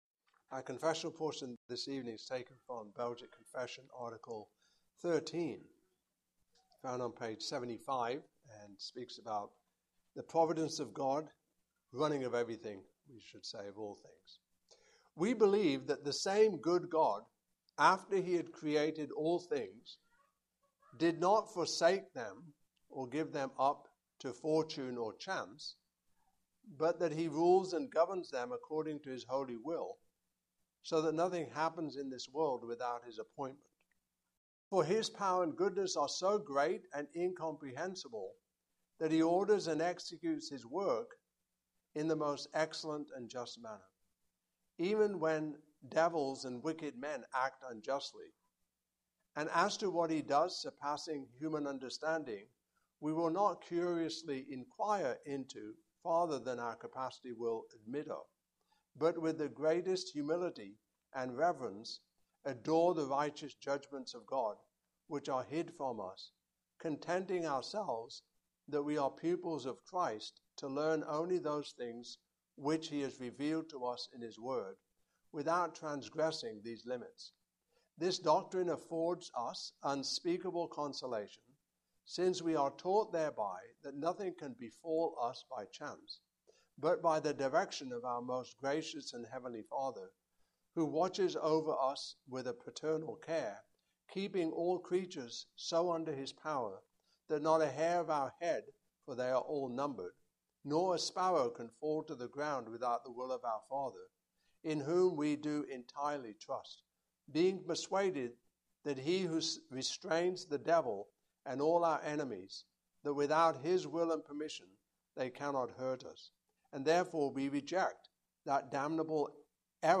Passage: Psalm 8:1-9 Service Type: Evening Service